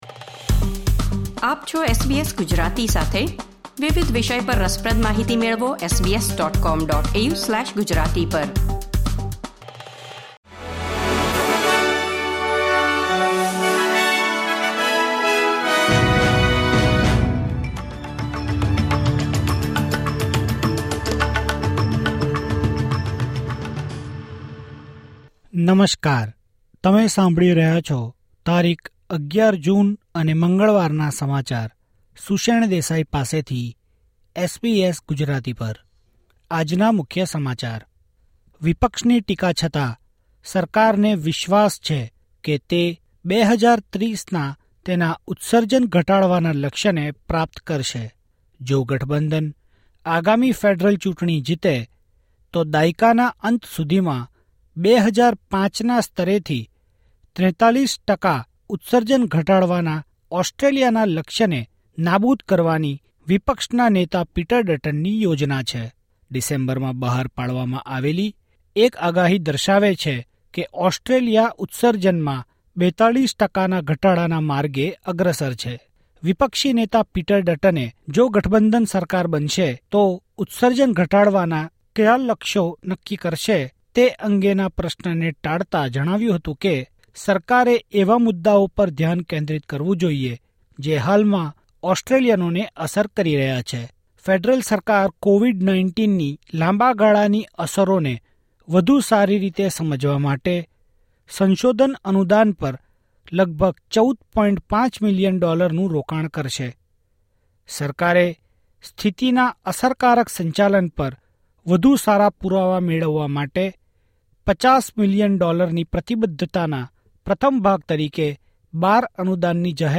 SBS Gujarati News Bulletin 11 June 2024